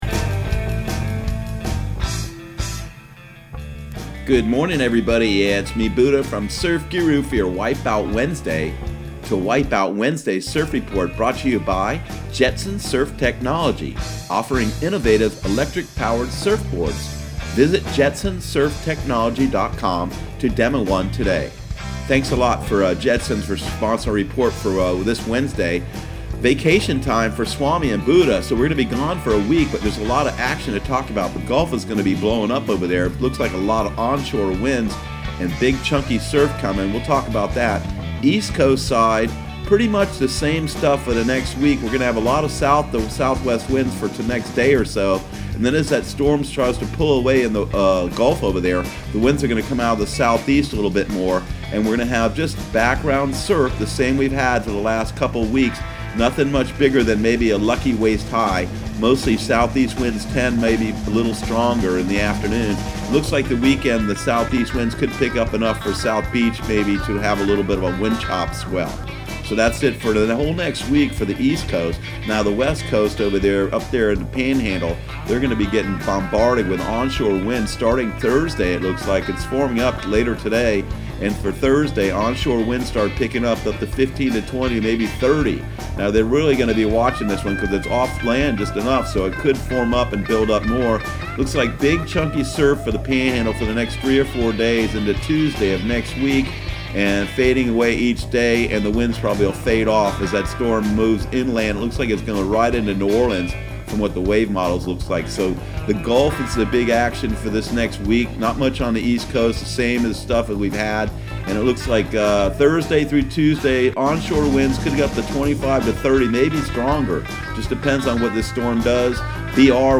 Surf Guru Surf Report and Forecast 07/10/2019 Audio surf report and surf forecast on July 10 for Central Florida and the Southeast.